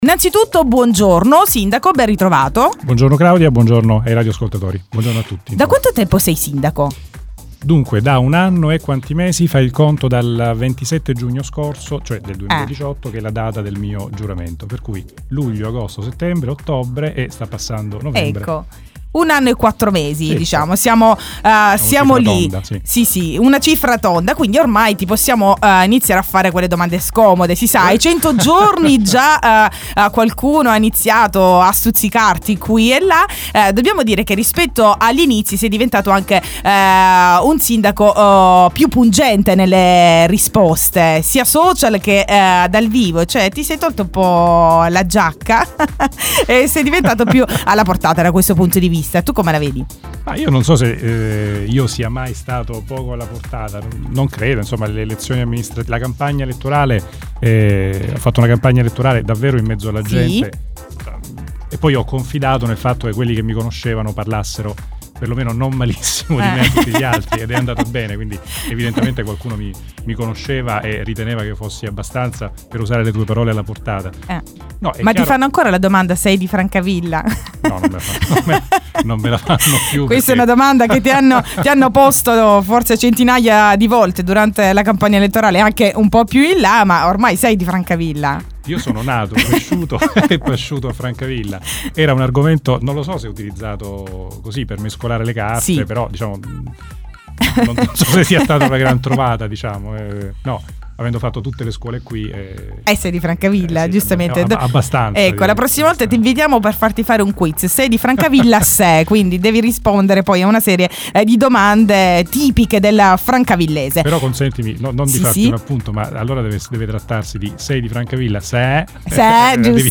Il sindaco Antonello Denuzzo ospite di Radio 85 per rispondere ai vari quesiti sui WORK IN PROGRESS .